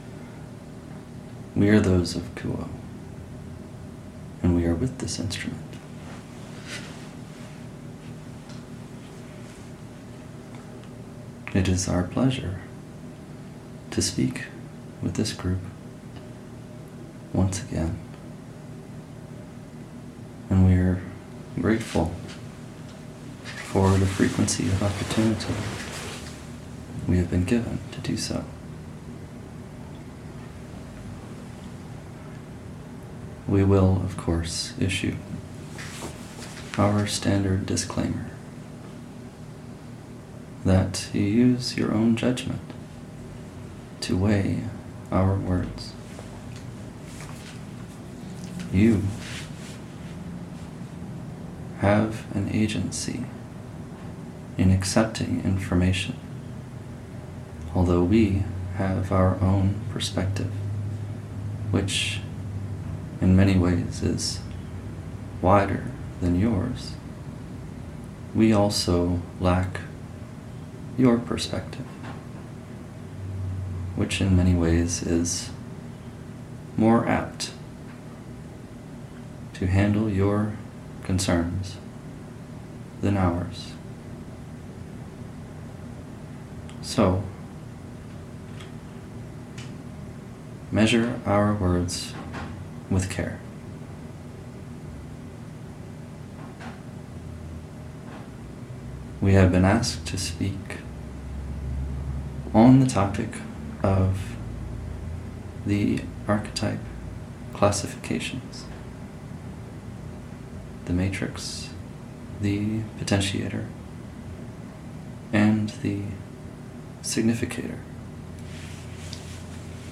In this session from the Other Selves Working Group’s first channeling intensive, Q’uo elaborates on functional characteristics involved in three of the seven classifications used to study the archetypal mind. The significator provides a unifying concept of selfhood that derives elaboration from the mutual reinforcement of matrix and potentiator, variously synthesizing the activity/passivity and generation/reception dynamics that accrue from their respective masculine or feminine correspondences.